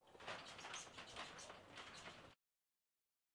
描述：摇晃大篷车
Tag: 抖动 风声 鸟声 杂音 交通 街道 八哥 氛围 汽车 悉索声中 现场 - 记录 噪声 低声